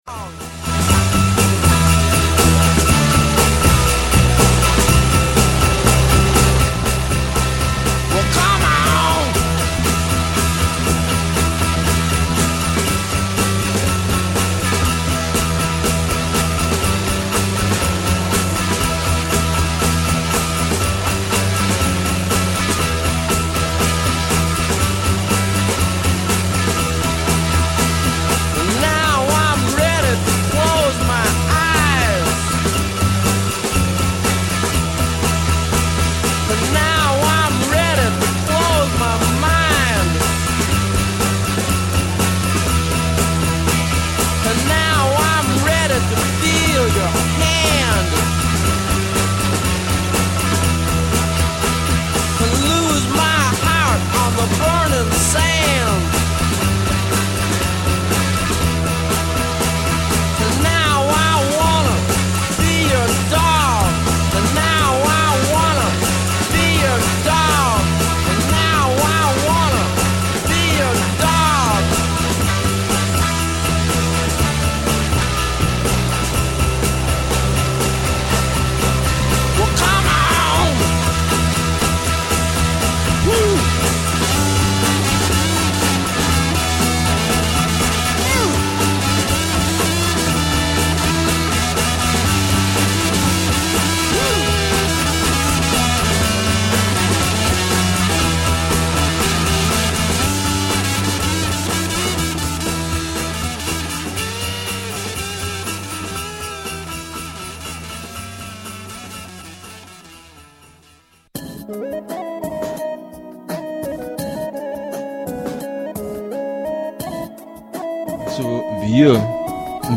Der Brunnen am Dorfplatz. Junge Auwiesener sprechen zum Wochenthema "Kulturelle Praktiken", singen live im Studio und erzählen über ihren Zugang zur Sprache.